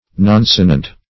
Nonsonant \Non*so"nant\